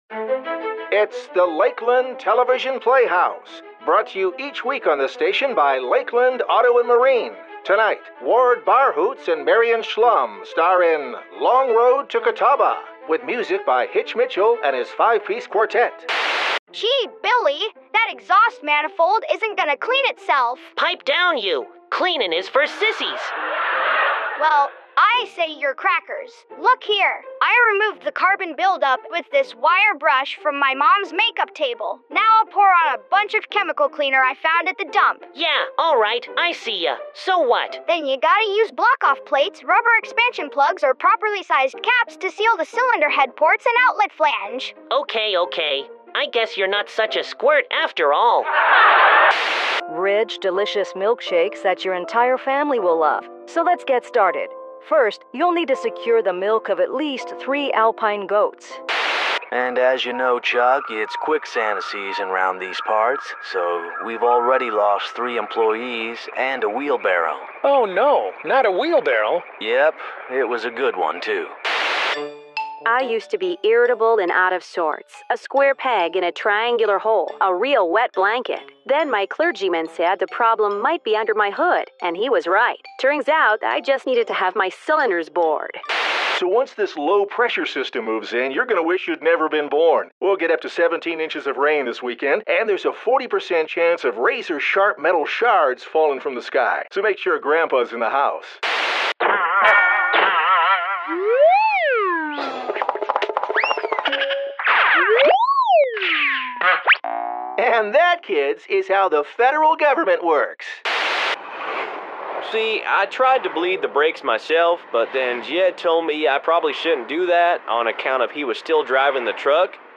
These productions focus on a single humorous theme and typically feature continuous voiceover and/or a stream-of-consciousness copy style.